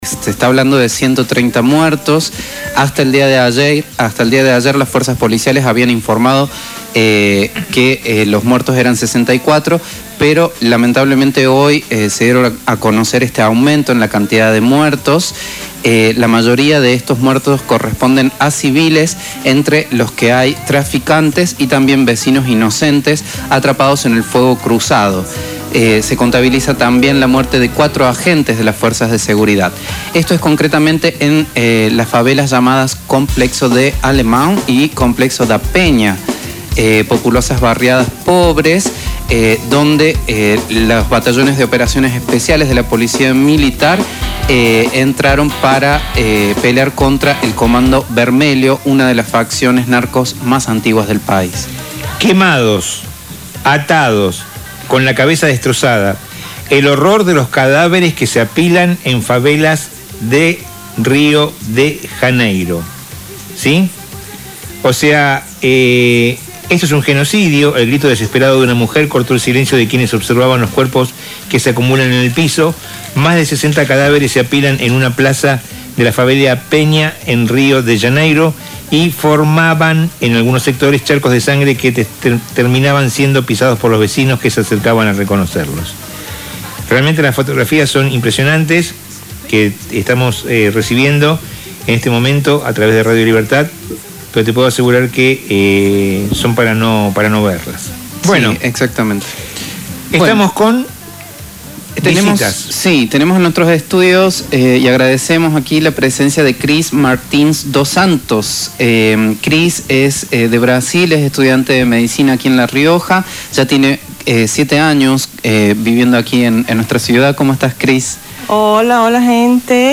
de visita en los estudios de Radio Libertad la Rioja